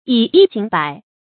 以一儆百 yǐ yī jǐng bǎi
以一儆百发音
成语注音 ㄧˇ ㄧ ㄐㄧㄥˇ ㄅㄞˇ